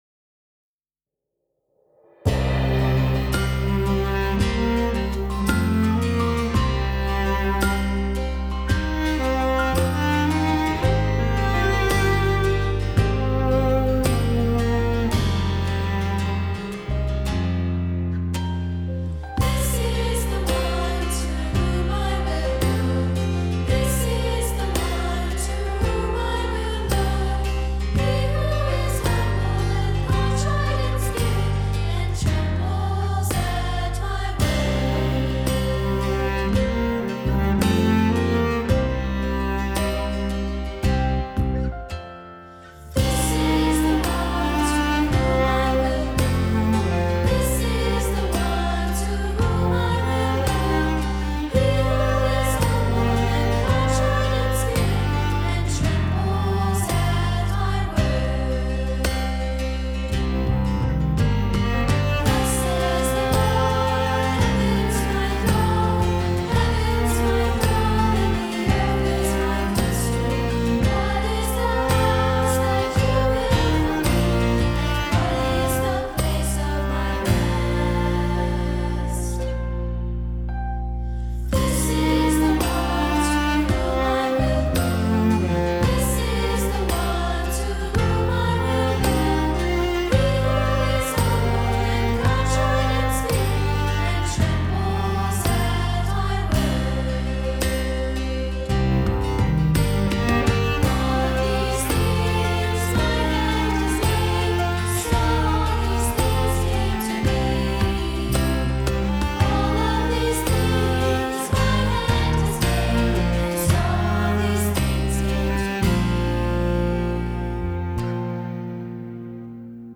It’s a setting of Isaiah 66:1–2 for children’s voices.
rhythm and cello
Key: Cm